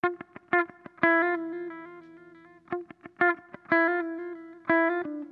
Sons et loops gratuits de guitares rythmiques 100bpm
Guitare rythmique 66